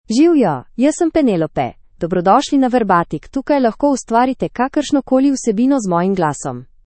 Penelope — Female Slovenian AI voice
Penelope is a female AI voice for Slovenian (Slovenia).
Voice sample
Listen to Penelope's female Slovenian voice.